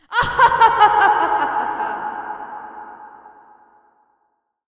女孩邪恶的笑声 " 邪恶的笑声 4
描述：来自Wayside School的Sideways Stories的录制带来了邪恶的笑声。混响补充道。
Tag: 闲扯 笑声 女孩 女性 邪恶的 女人